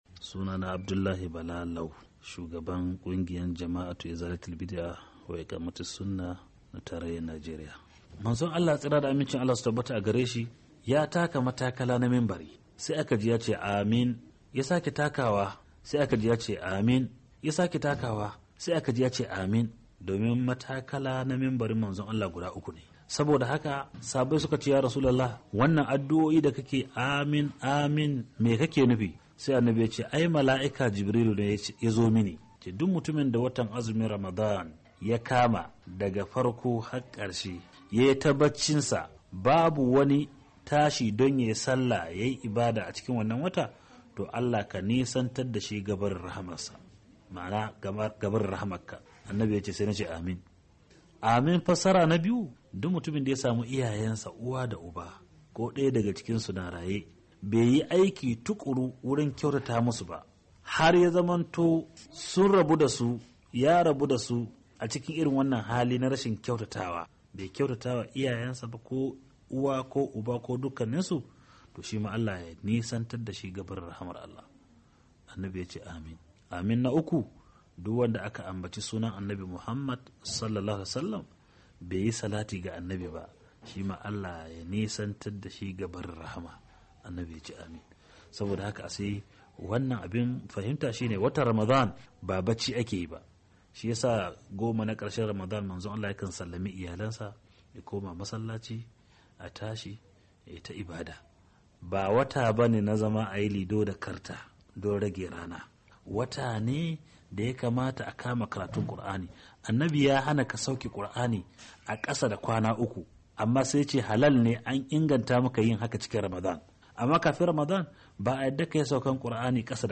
Tafsirin